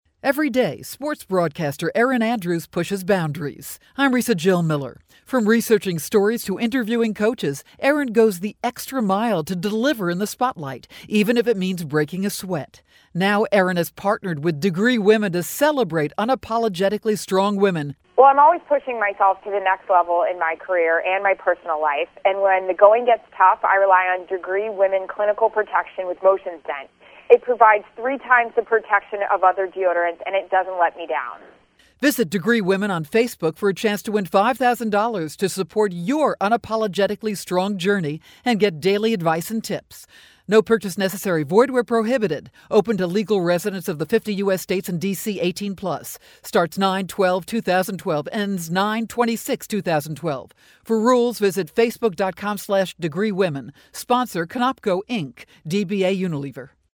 September 19, 2012Posted in: Audio News Release